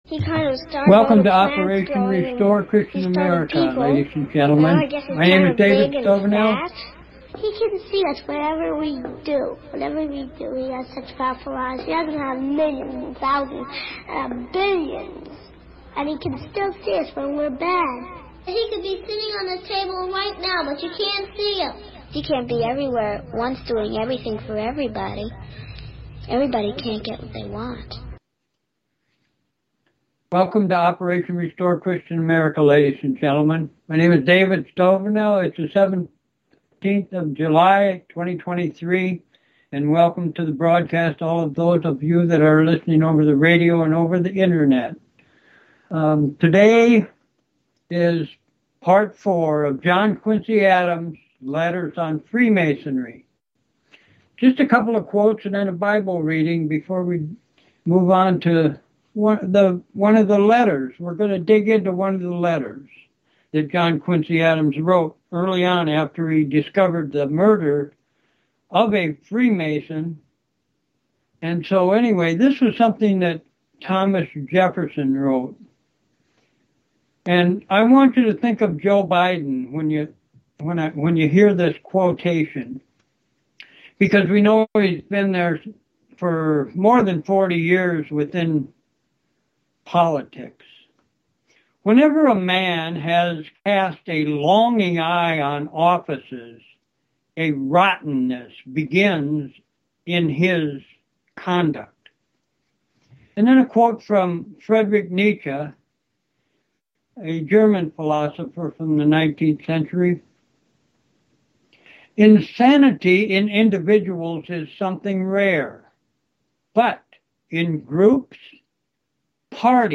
John Quincy Adams – Letters on Freemasonry Part 4 on this Monday 17 July 2023 was extremely important in that some of the letters that he wrote were read to provide the proof that Freemasonry was and is a pervasive cult that causes Christian Virtue and Christian Culture to be violated. Staying out of Freemasonry is the remedy to this insidious problem.